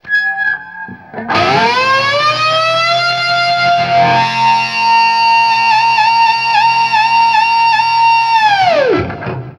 DIVEBOMB10-L.wav